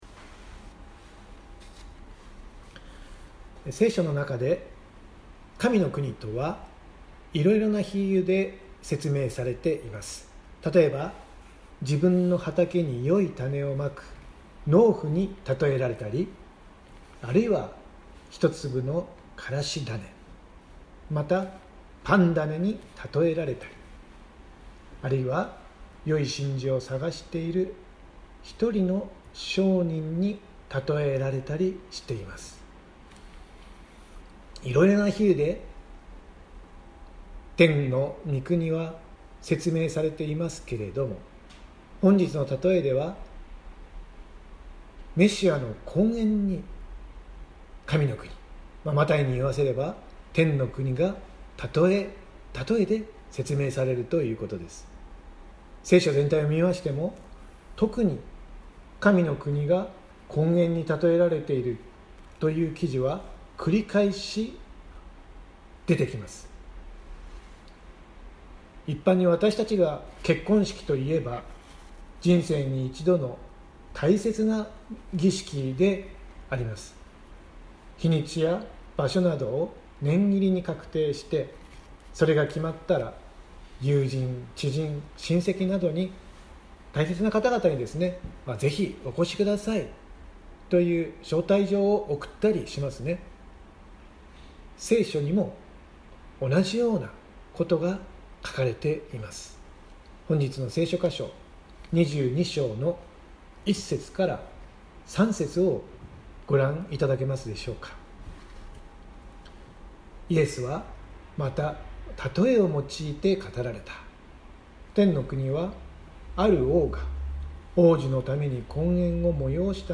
千間台教会。説教アーカイブ。
音声ファイル 礼拝説教を録音した音声ファイルを公開しています。